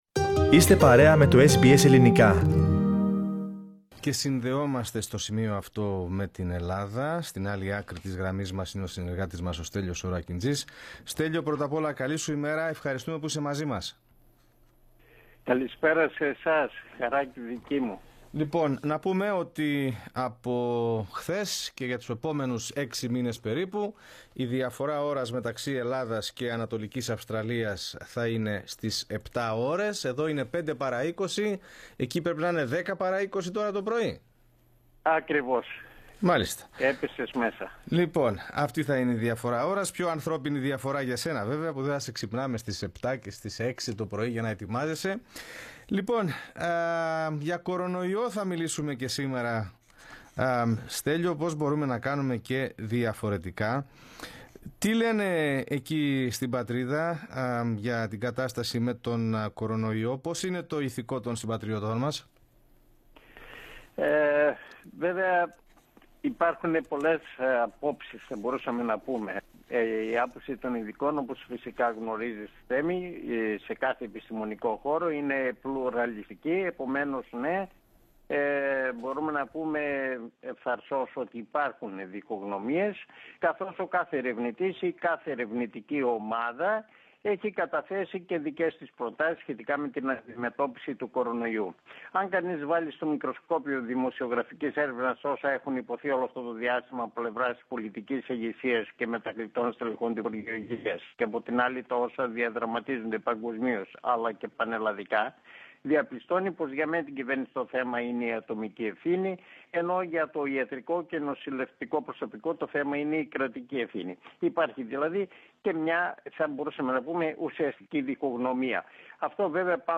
report_from_greece_0.mp3